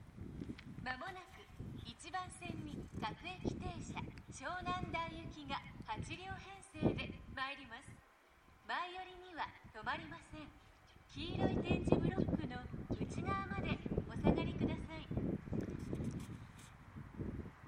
接近放送各駅停車　湘南台行き接近放送です。